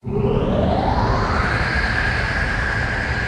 Play Vaccuum Cleaner - SoundBoardGuy
Play, download and share vaccuum cleaner original sound button!!!!
vaccuum-cleaner.mp3